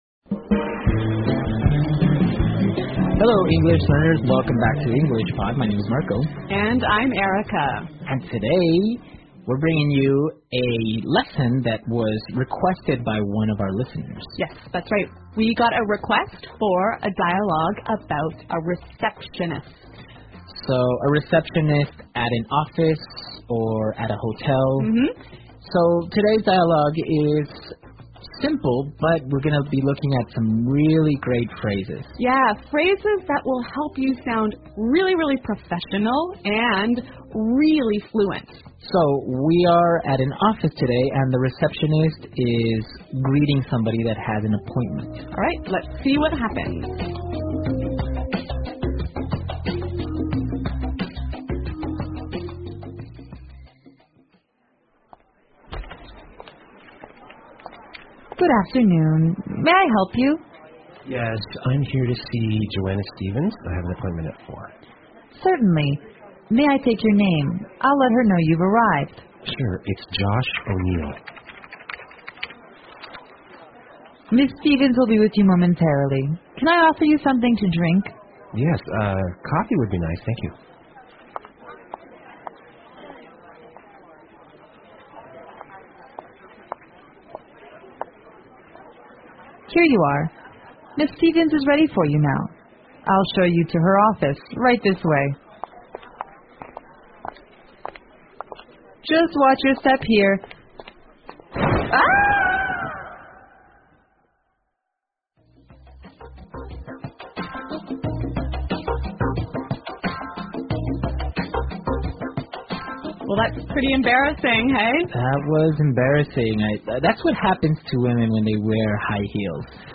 纯正地道美语 第63期(外教讲解):ReceptionistMP3音频下载,资料来源于EnglishPod，内容覆盖生活、学习、工作、娱乐等各个方面的小专题，纯正地道的美音，内容非常的实用，希望对大家喜欢。